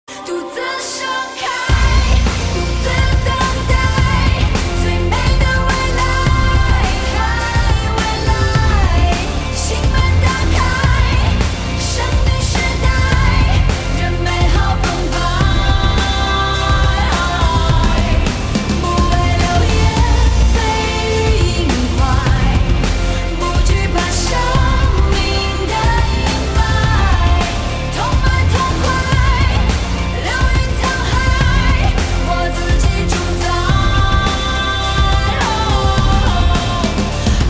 M4R铃声, MP3铃声, 华语歌曲 31 首发日期：2018-05-14 12:34 星期一